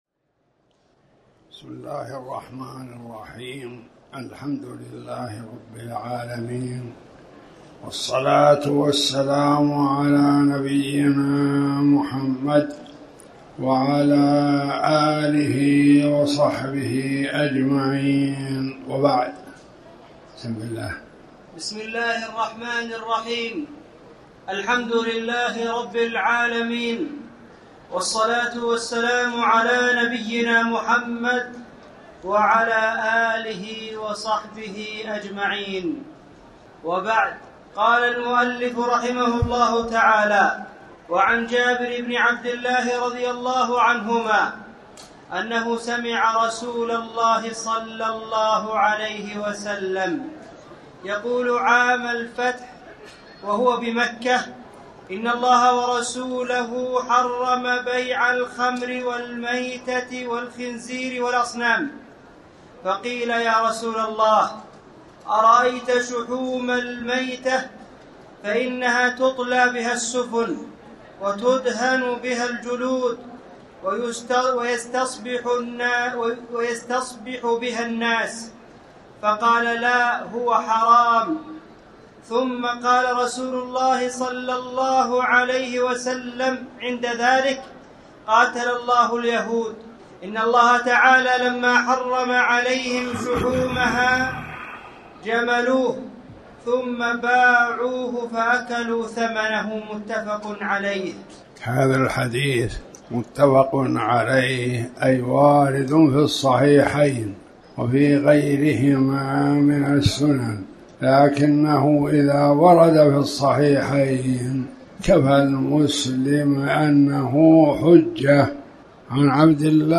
تاريخ النشر ٢٠ ربيع الثاني ١٤٣٩ هـ المكان: المسجد الحرام الشيخ